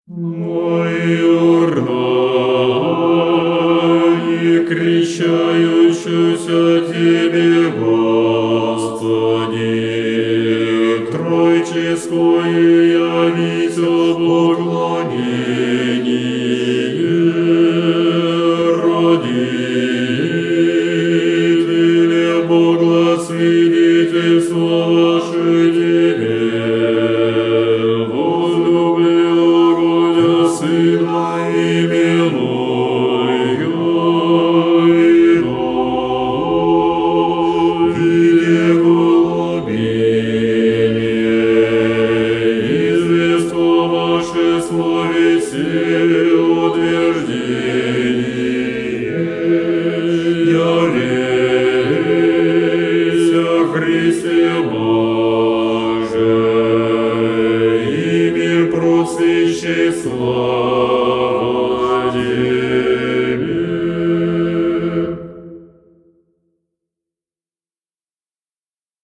Tropar-krtescheniye_gospodne.ogg  (размер файла: 1,06 Мб, MIME-тип: application/ogg ) Тропарь Крещения Господня История файла Нажмите на дату/время, чтобы просмотреть, как тогда выглядел файл.
Tropar-krtescheniye_gospodne.ogg